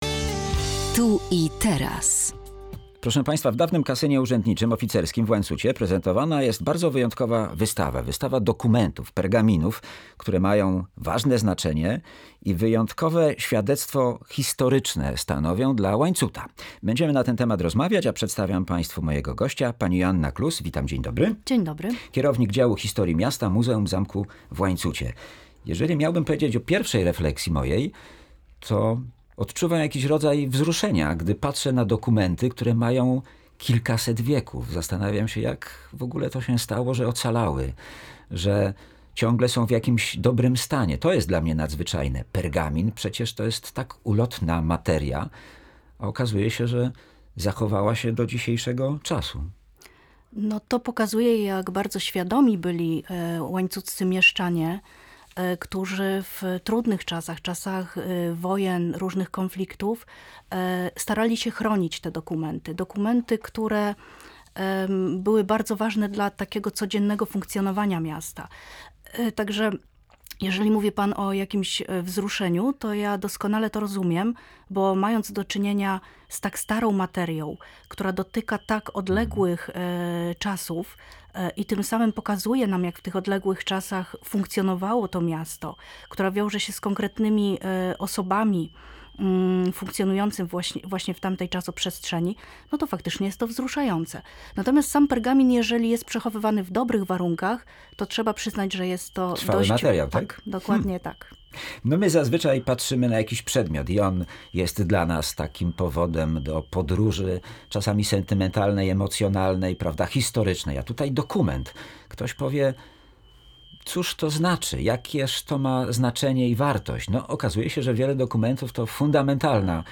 Większość z nich to przywileje cechowe. Podczas rozmowy dowiedzą się Państwo jakie mają znaczenie i co mówią o historii Łańcuta.